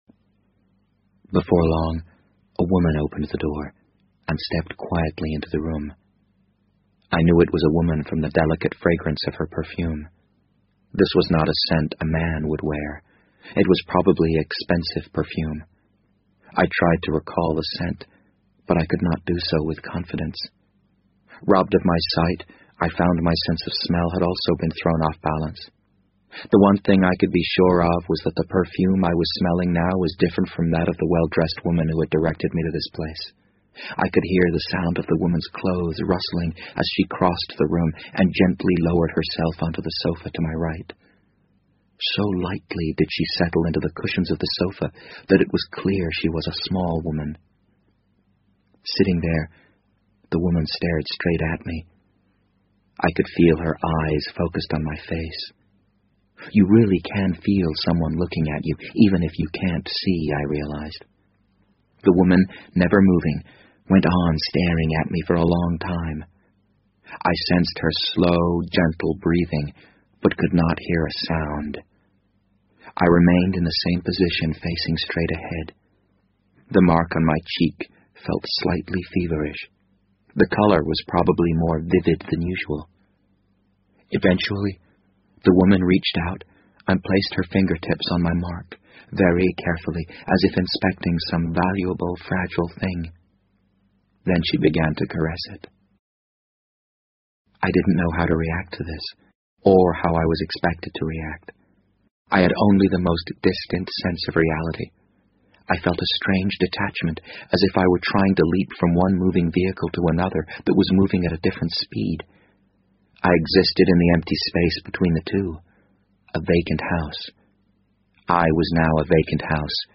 BBC英文广播剧在线听 The Wind Up Bird 009 - 19 听力文件下载—在线英语听力室